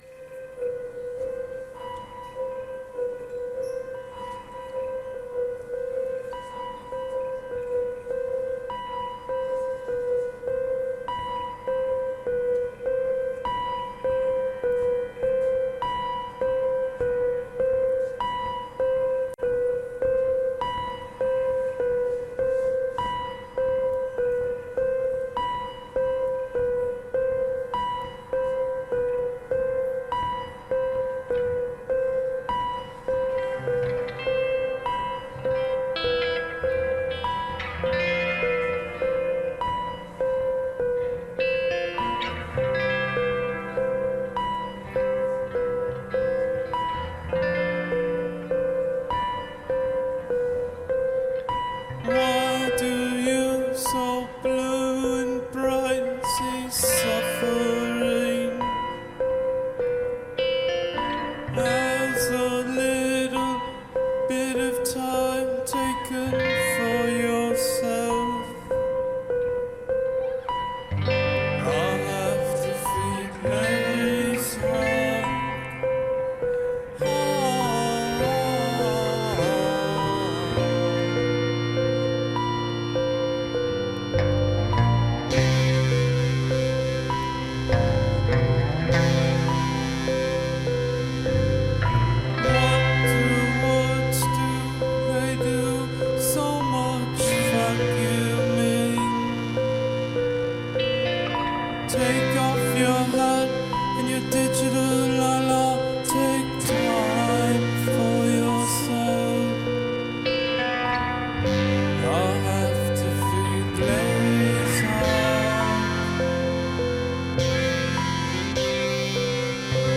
Live At Route du Rock 2019 In St. Malo, France
Live at Route du Rock, St. Malo, France